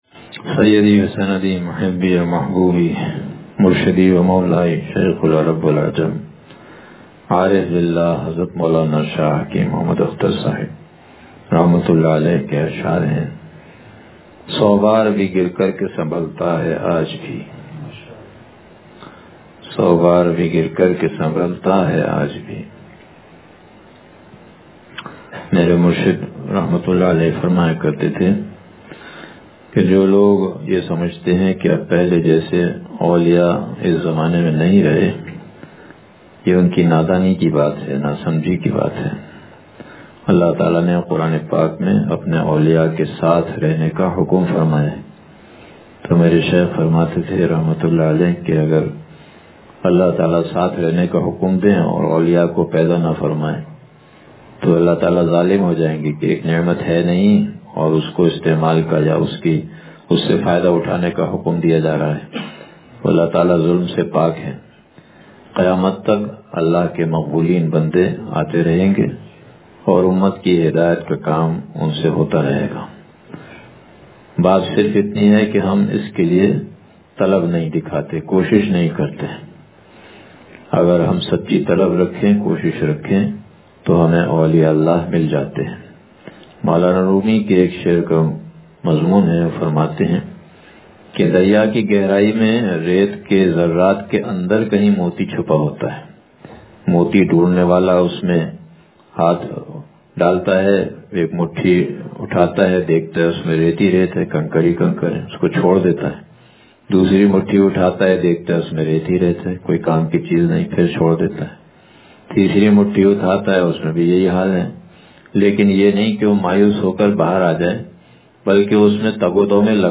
سو بار بھی گر کر کے سنبھلتا ہے آج بھی – مجلس بروز بدھ